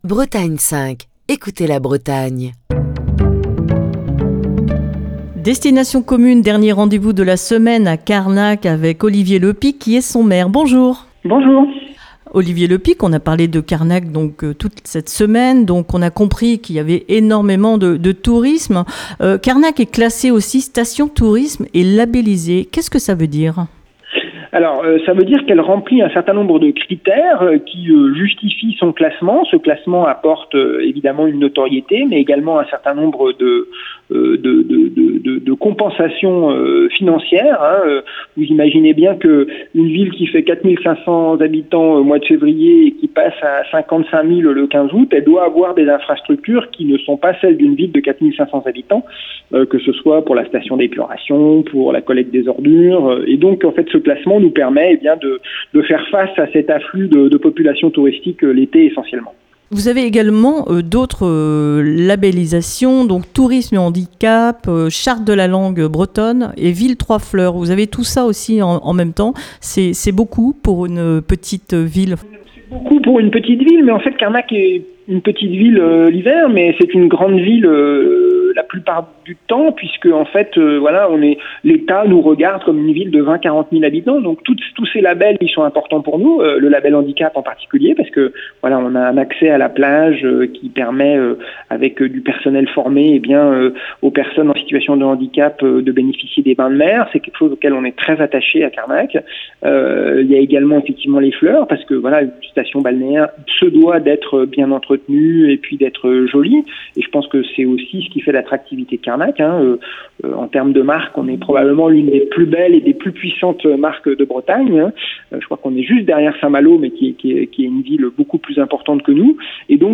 Ce matin, dernier rendez-vous à Carnac, dans le Morbihan où Destination commune à posé ses micros cette semaine.